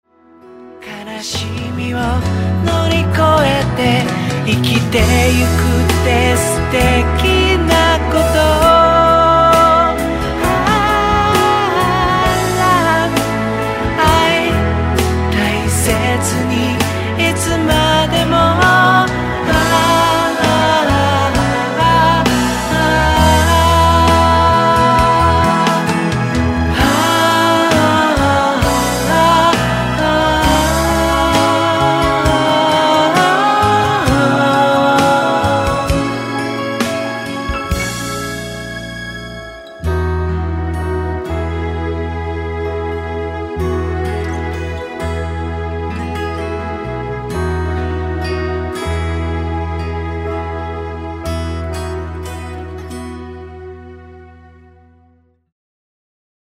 懐かしいサウンド、あたたかい歌声。
「大人のための童謡」